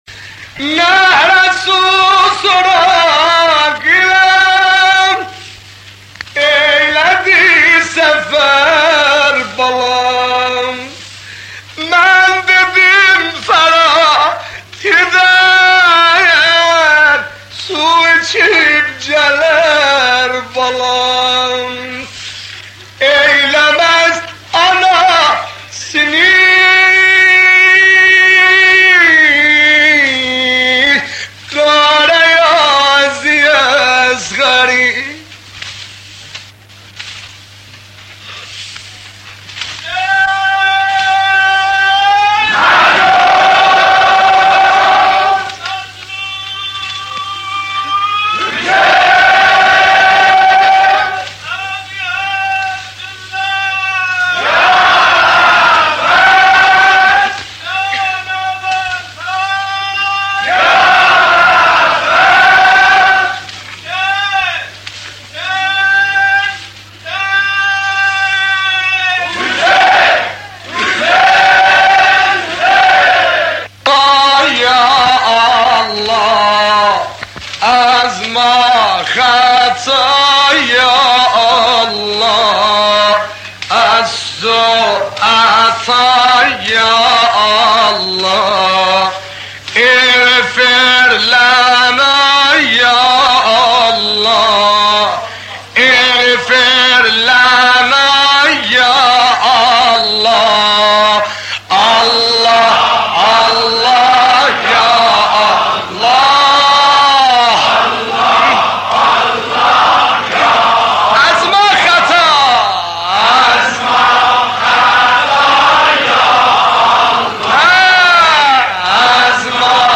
اجرا شده در عاشورای سال 1353 ش